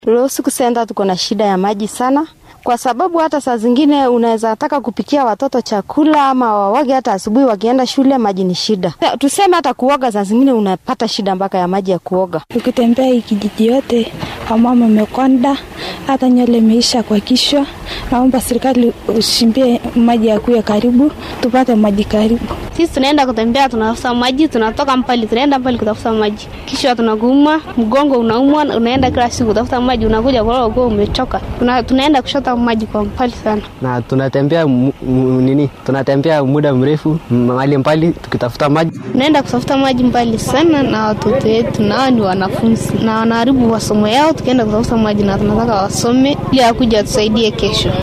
Waa kuwaan qaar ka mid ah dadka ay biyo la’aantu saameysay oo warbaahinta la hadlay